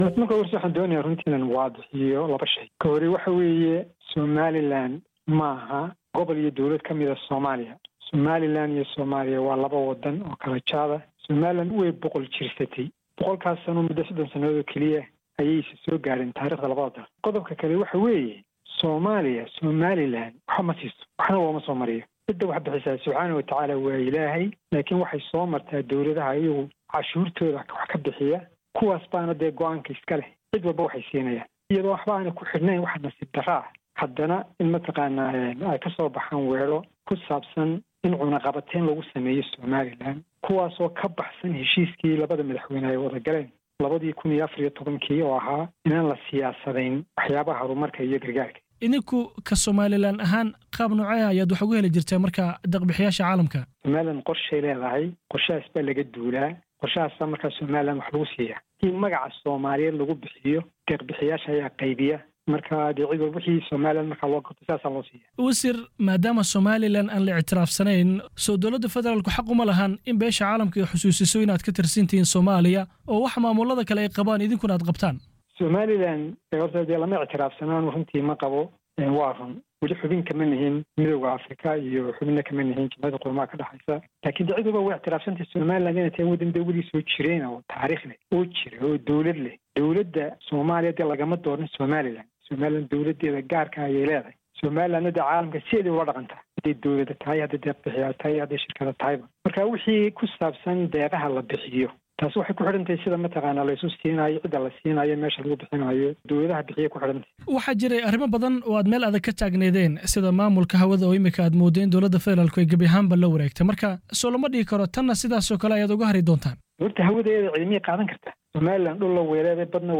Wasiirka Arrimaha Dibadda ee Somaliland Sacad Cali Shire oo la hadlayay BBC-da ayaa sheegay in Somaliland aysan ahayn maamul ka mid ah Somalia inteeda kale, isagoo nasiib-darro ku tilmaamay in Somalia ay doonayso inay cunno-qabteyso Somaliland.